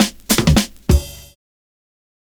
Index of /90_sSampleCDs/USB Soundscan vol.46 - 70_s Breakbeats [AKAI] 1CD/Partition B/20-100FILLS3
100FILLS03.wav